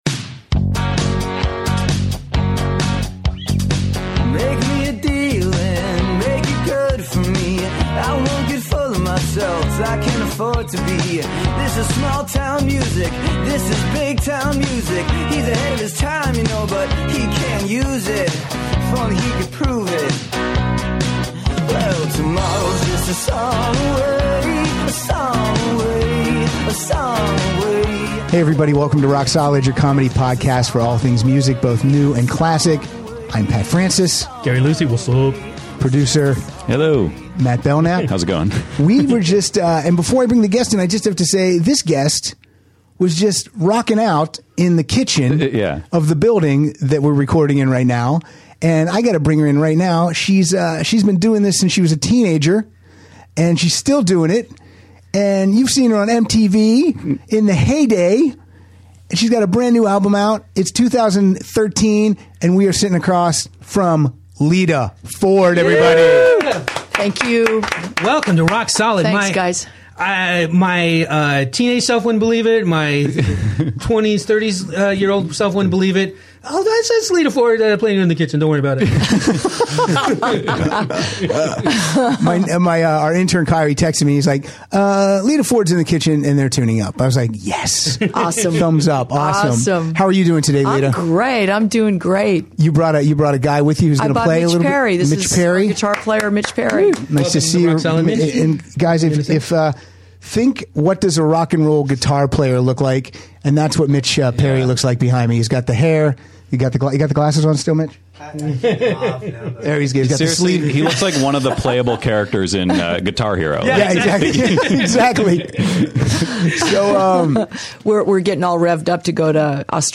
Rock legend Lita Ford drops by to chat and perform two songs in studio on this very special 100th episode of Rock Solid.